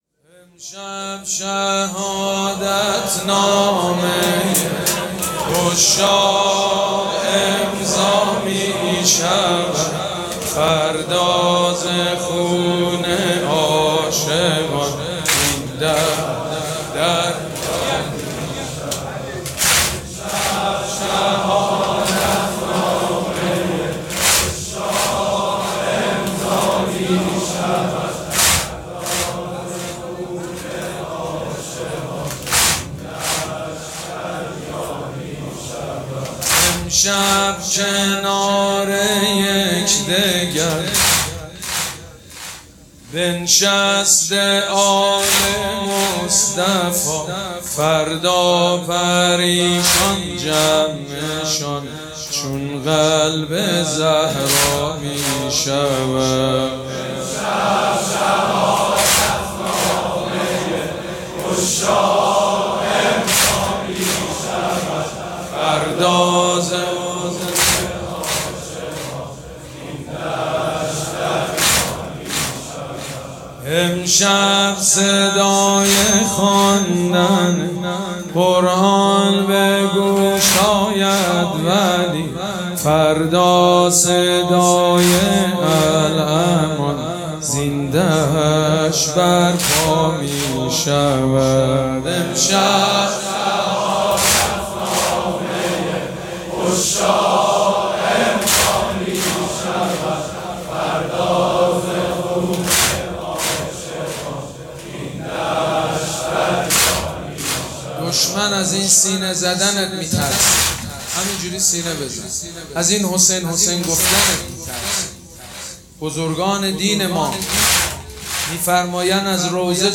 مراسم عزاداری شب دهم محرم الحرام ۱۴۴۷
حاج سید مجید بنی فاطمه